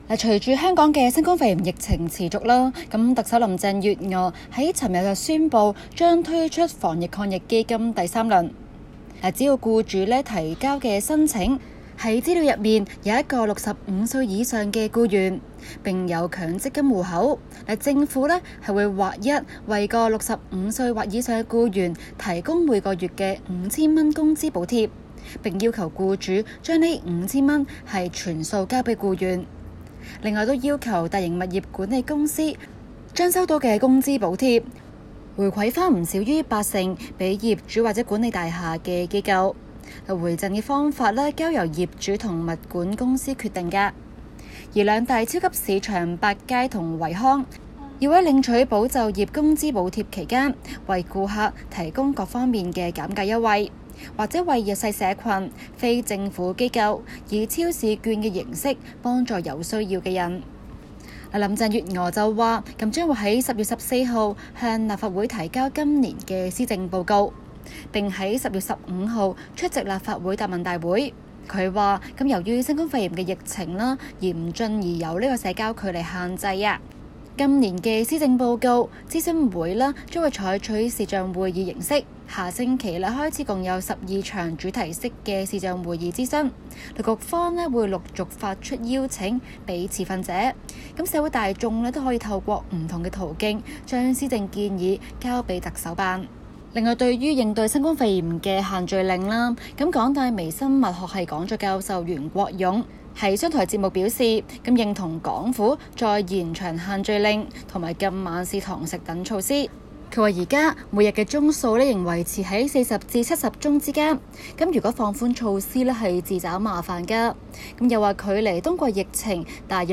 今期【中港快訊 】環節報道港府推出第三輪防疫基金， 舒民解困。